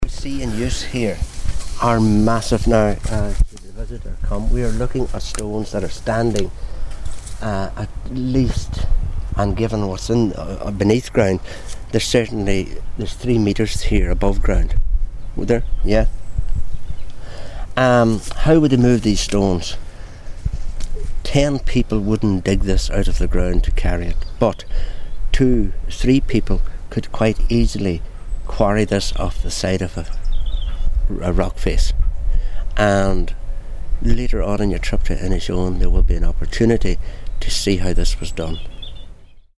out and about in Inishowen